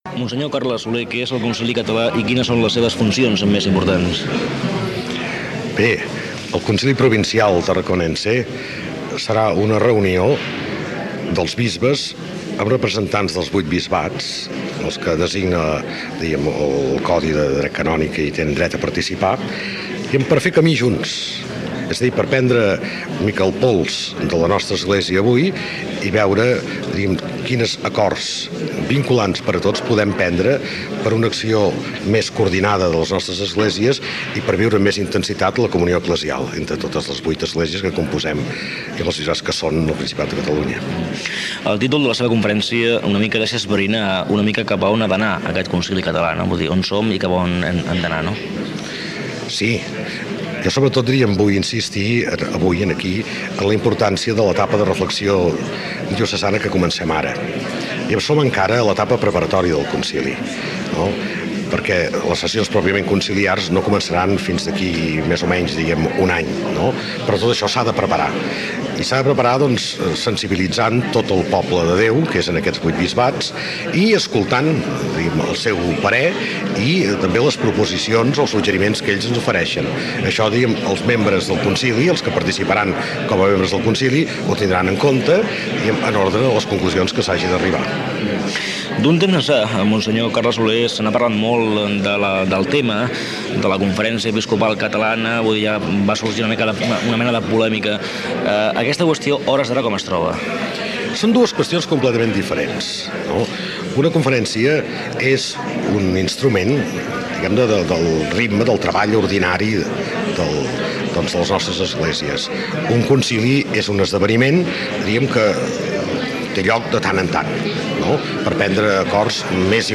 Fragment de l'entrevista al bisbe Carles Soler sobre el concili català
Informatiu